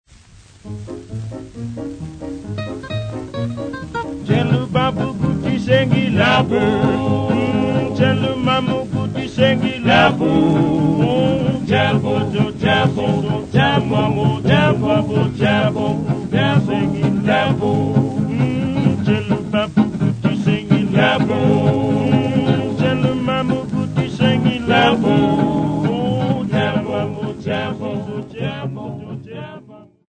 Popular music--Africa
sound recording-musical
Swing jazz band song
Vestax BDT-2500 belt drive turntable